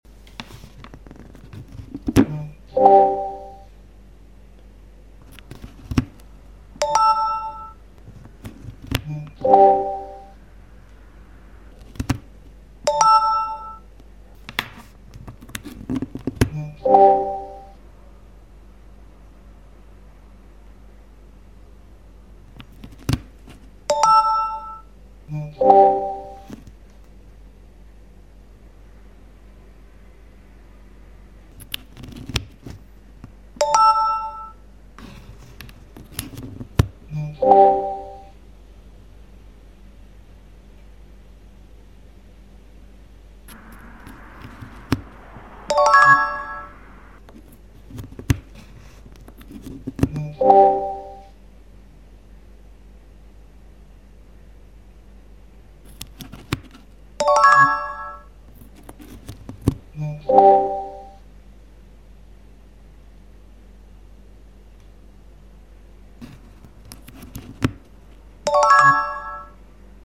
Galaxy S25 Ultra vs Honor Magic7 Pro Charging Sounds…